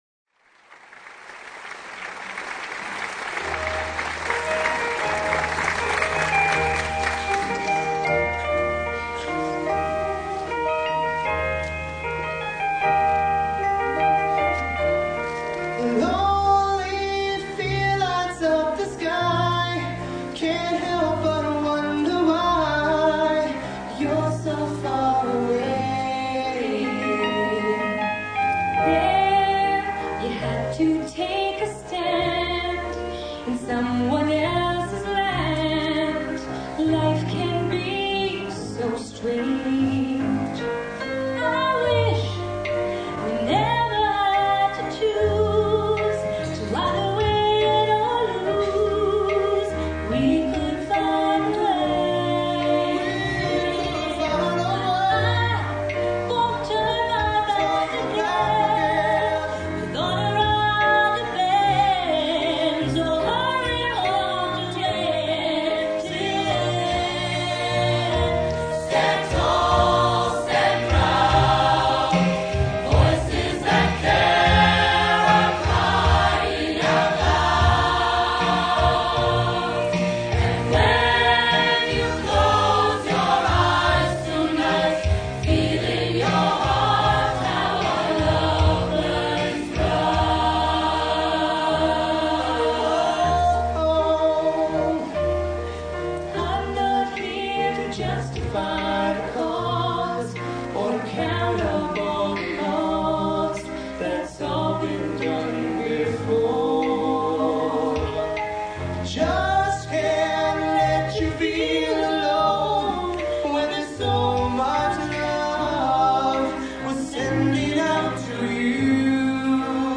performed live and in color in 1991